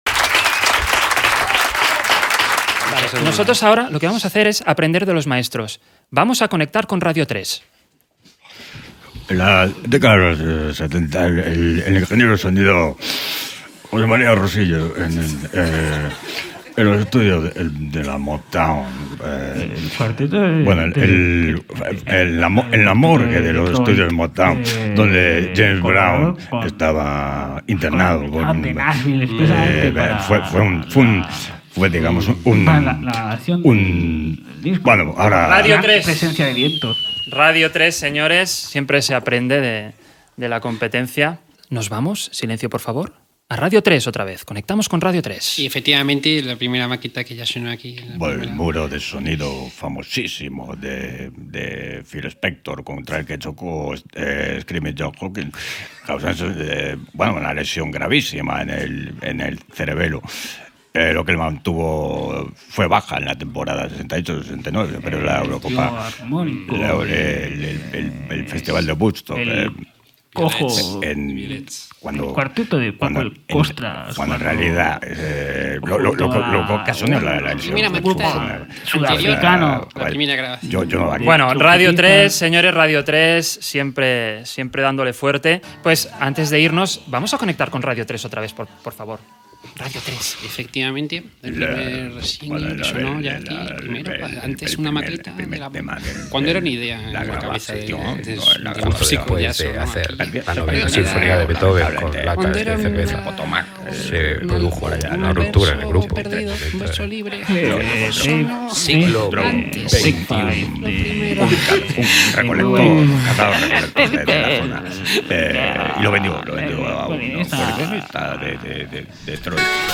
Paròdia de la programació de Radio 3 de RNE
Entreteniment